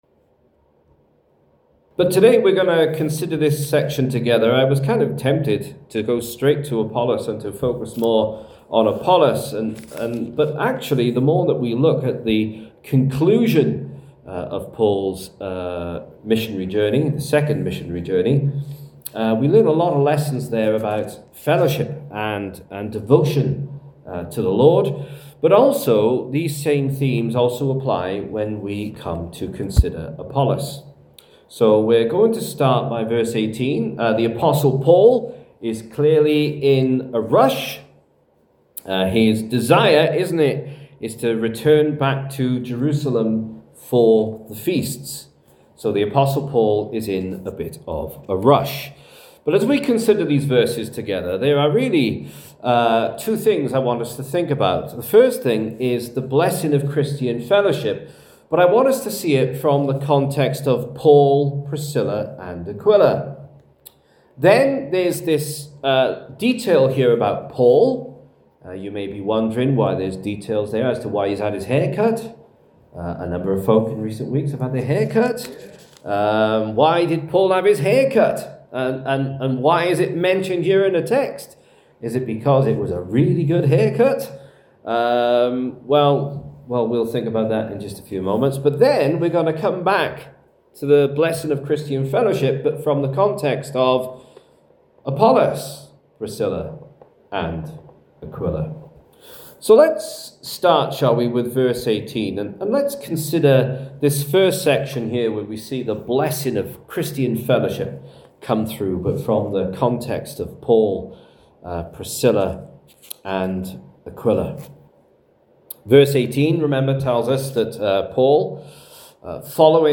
Sermon: The Blessing of Christian Fellowship (Acts 18:18-28) – Union Croft Chapel
This sermon was preached at Union Croft on 16th November 2025.